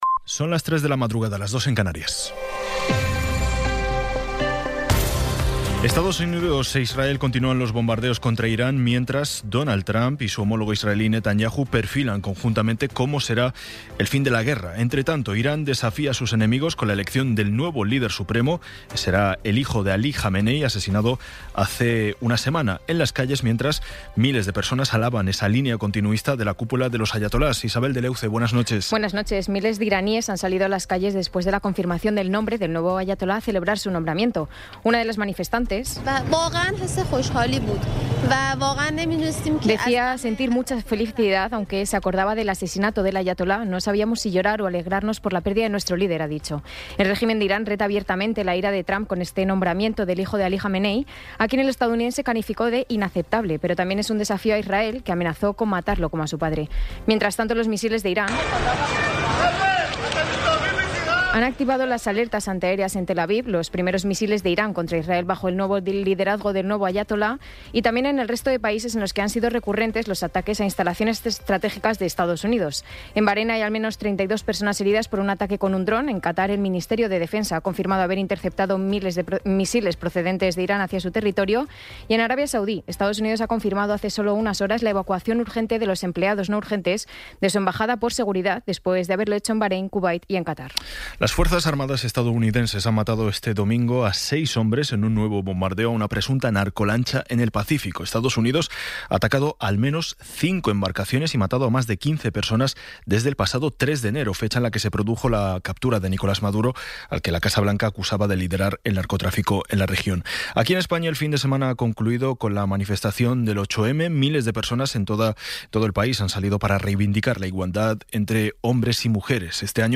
Resumen informativo con las noticias más destacadas del 09 de marzo de 2026 a las tres de la mañana.